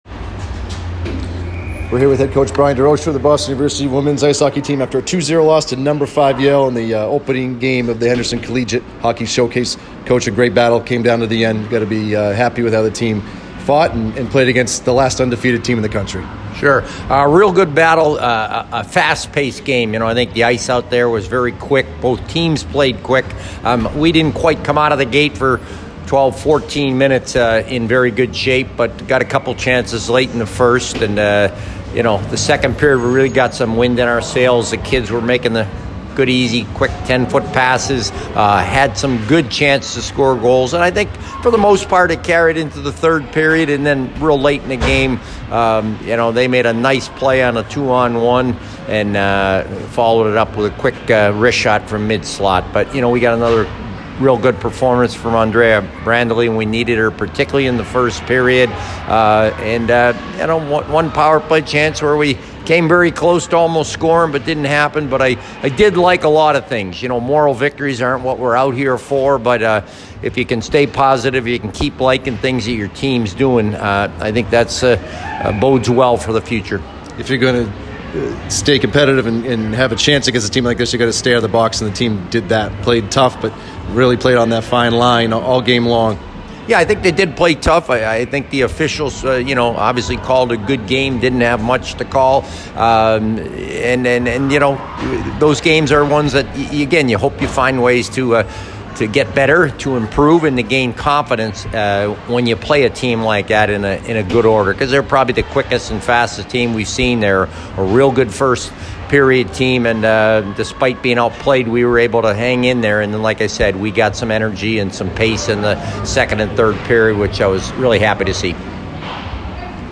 Women’s Ice Hockey / Yale Postgame Interview